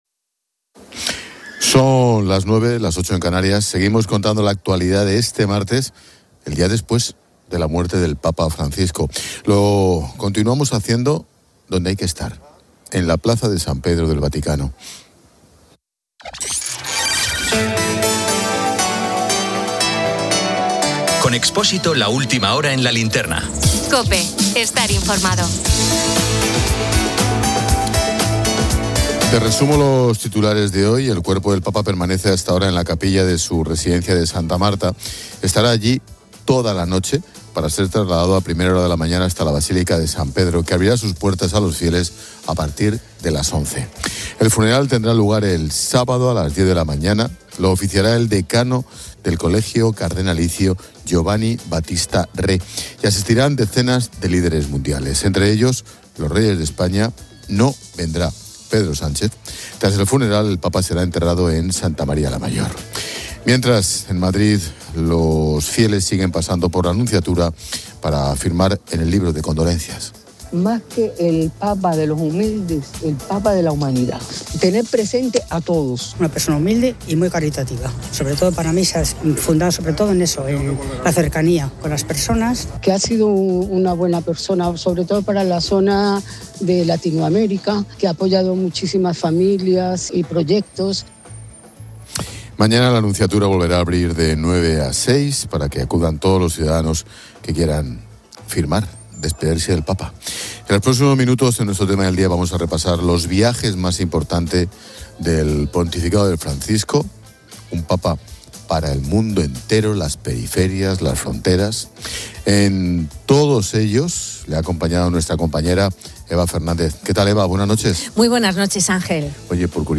Lo continuamos haciendo donde hay que estar, en la Plaza de San Pedro del Vaticano.